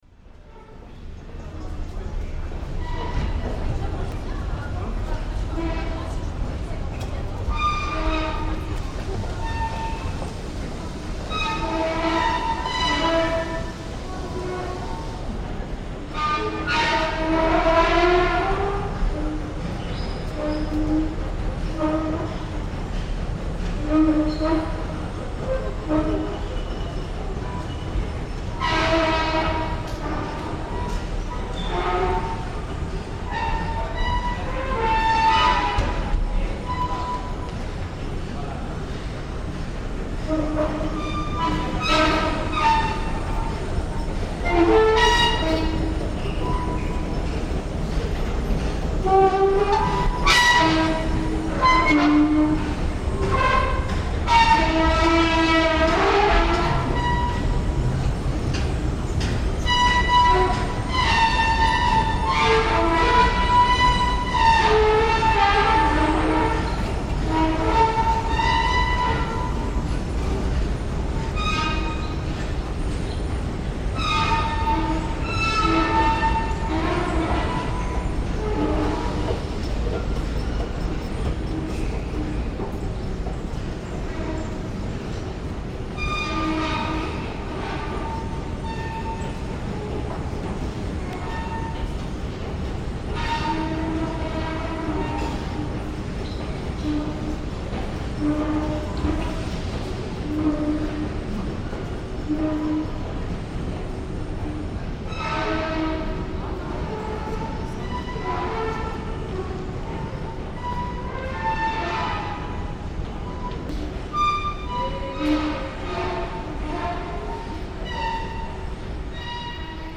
BALLADE A L'ESCALATOR ST LAZARE